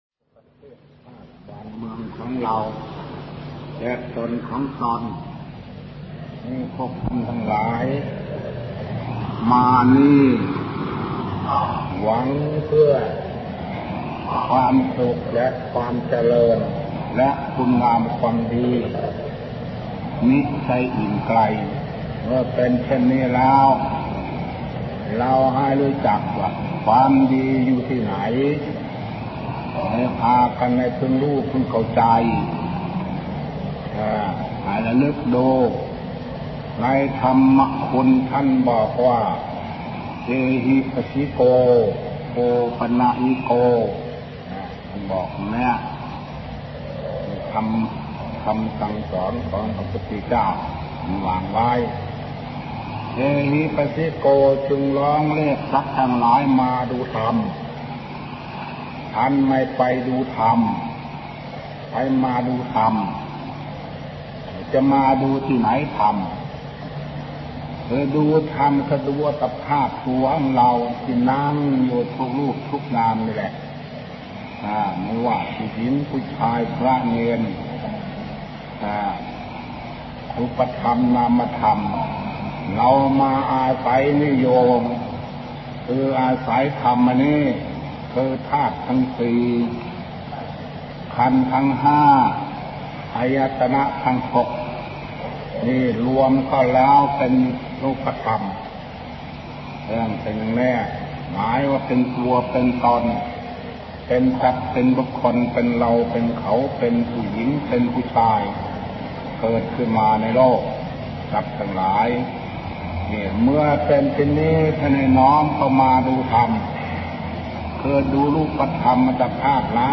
หลวงปู่ฝั้น อาจาโร - เสียงธรรม : ธรรมะไทย